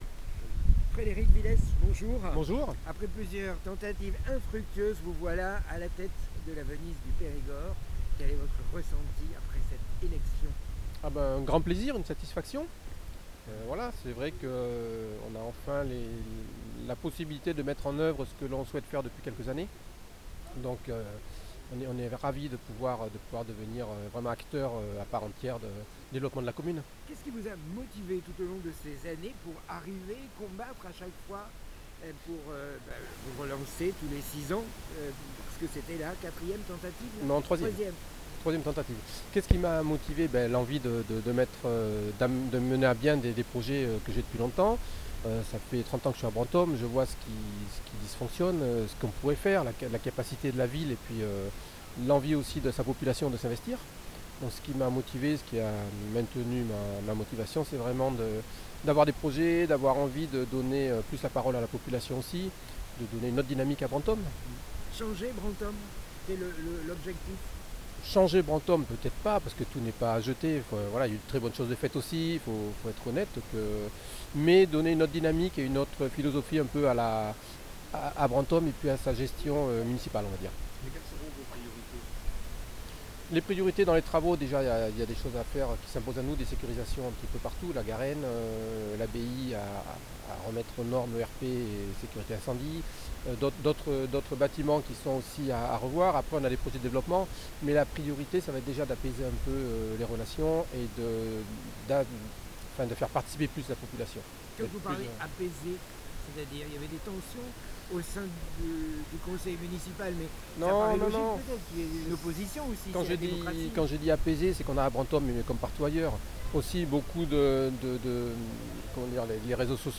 FREDERIC VILHES nouveau maire de BRANTOME EN PERIGORD s'exprime sur LIBERTE FM